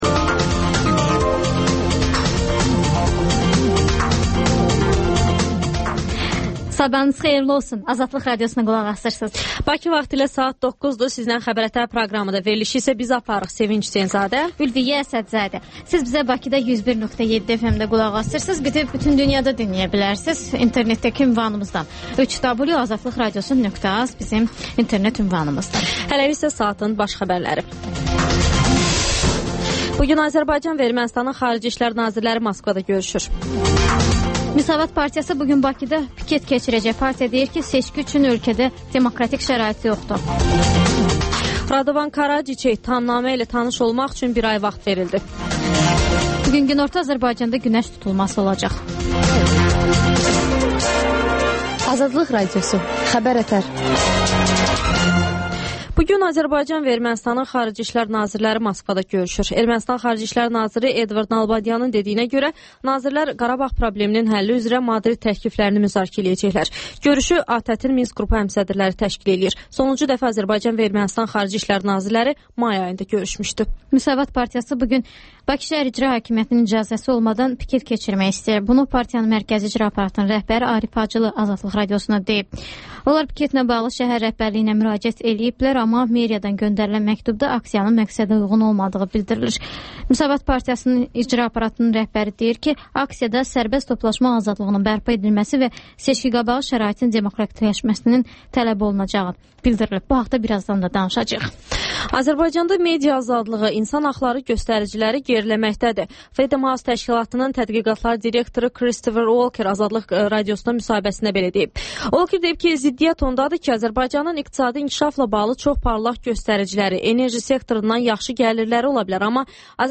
Xəbər-ətər: xəbərlər, müsahibələr və 14-24: Gənclər üçün xüsusi veriliş